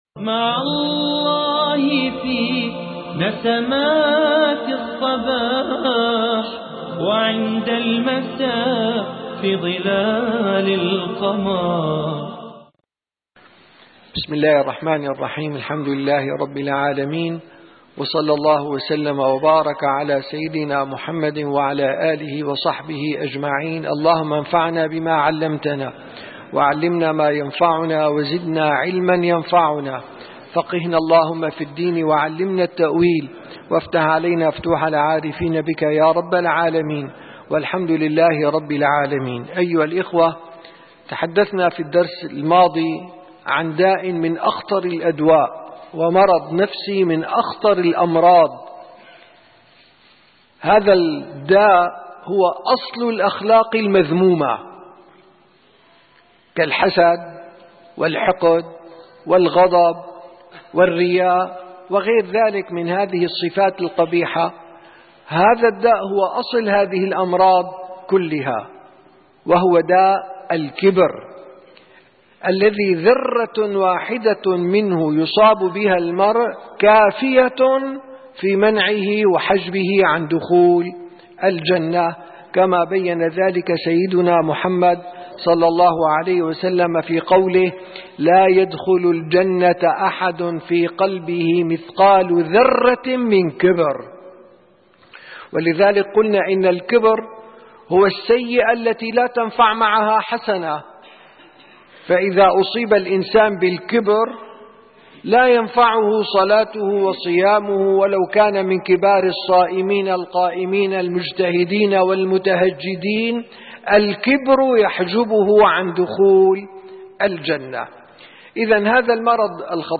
10 - درس جلسة الصفا: تشخيص داء الكبر